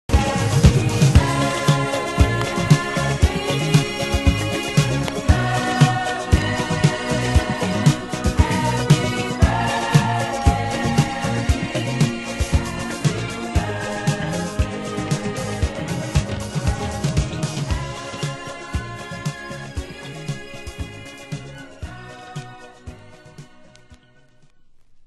有/少しチリパチノイズ有　　ジャケ：良好